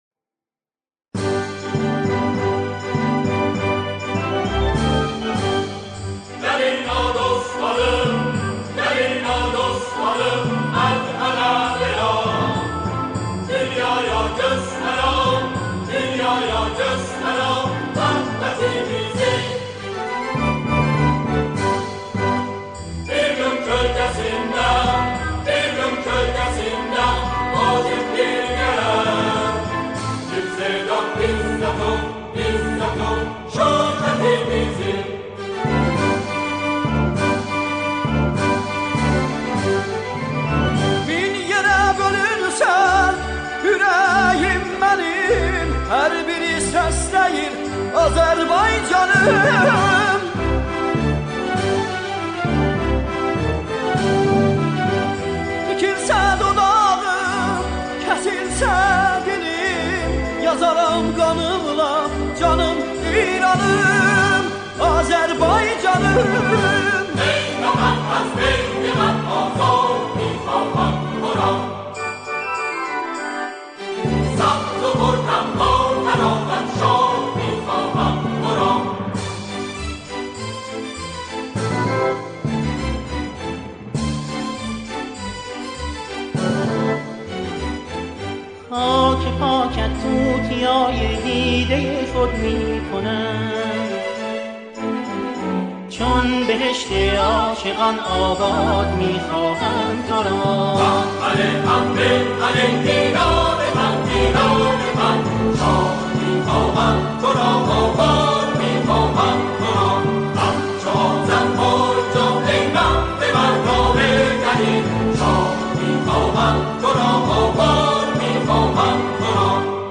سرودهای شهرها و استانها
همخوانی